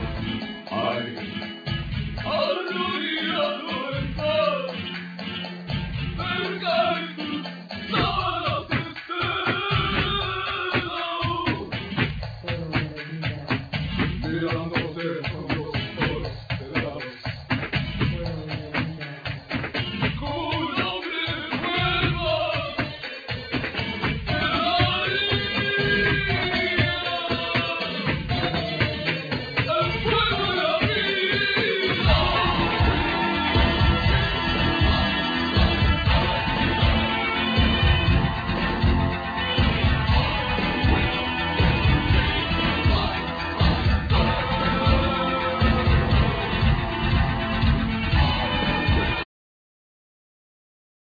Saxphone,Machines
Flamenco guitar,Palms
Lead & Backing Vocal
Zapateados,Palms